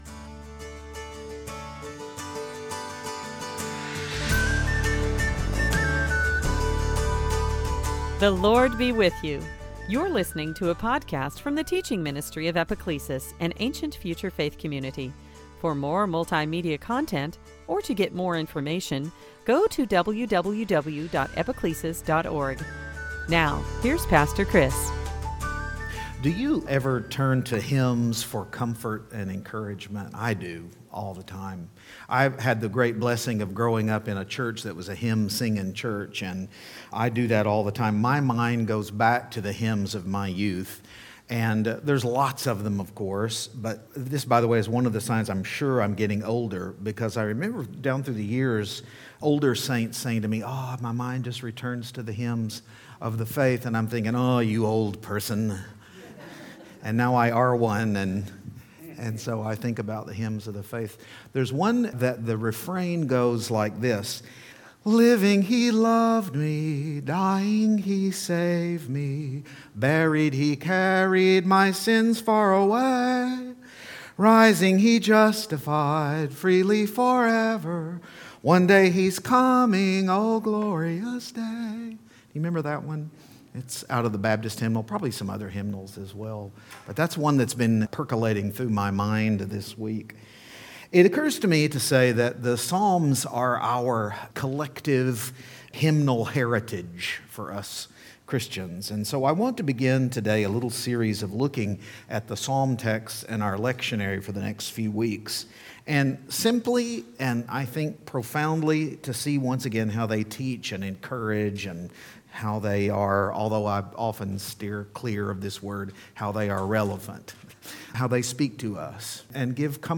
This sermon began a series of looking at the psalm texts in our lectionary readings.